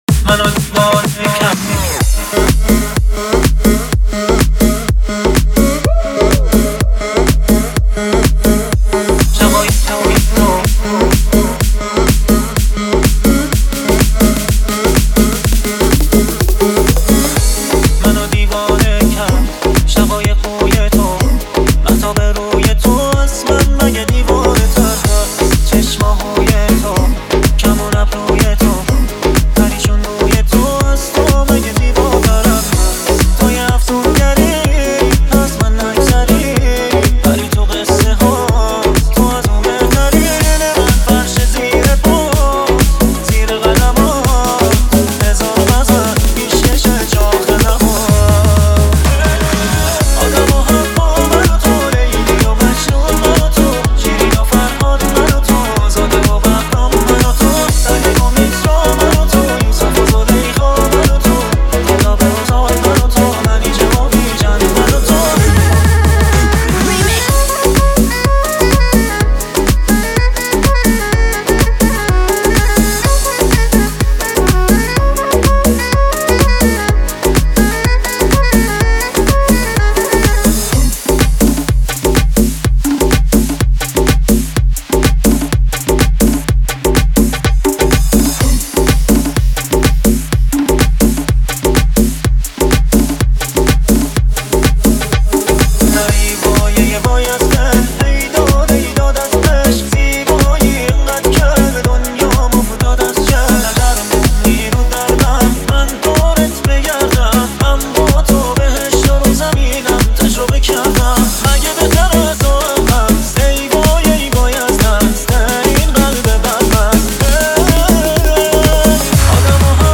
• آهنگ جدید ~ ریمیکس